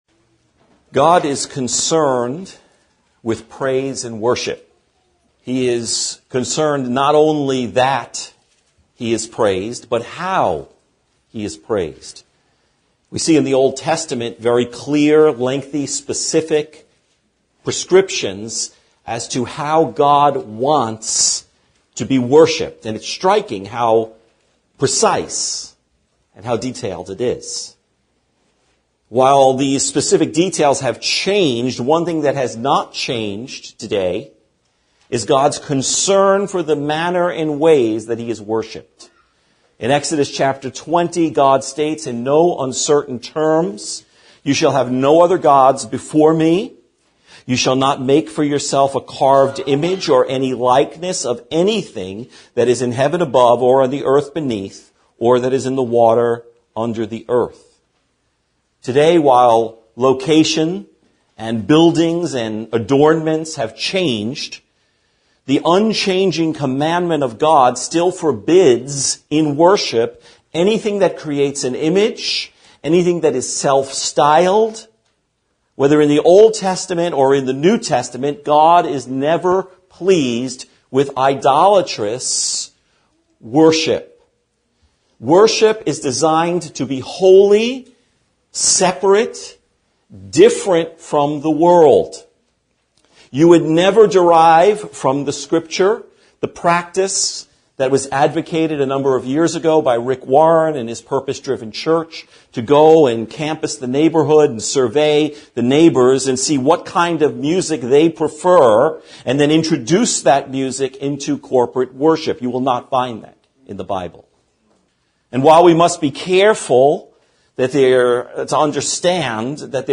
Praise Befits the Righteous | SermonAudio Broadcaster is Live View the Live Stream Share this sermon Disabled by adblocker Copy URL Copied!